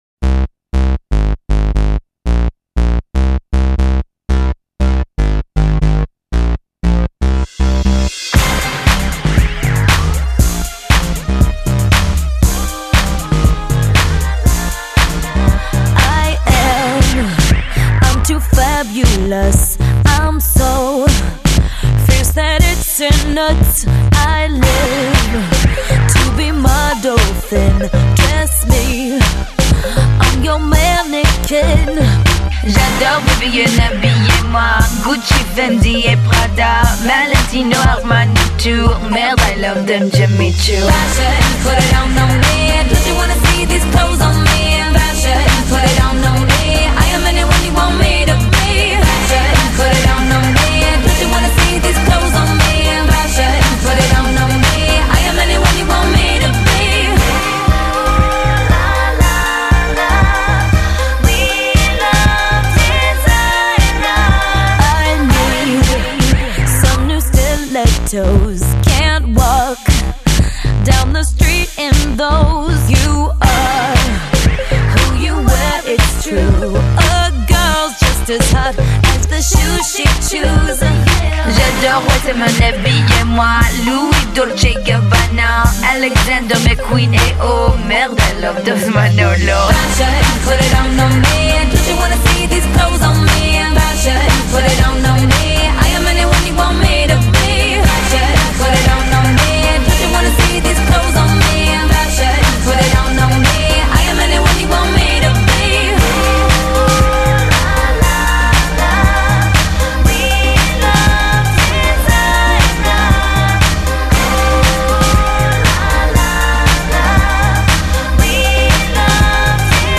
зарубежная эстрада